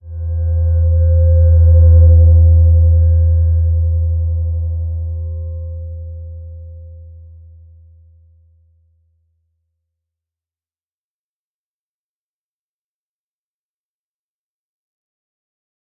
Slow-Distant-Chime-E2-f.wav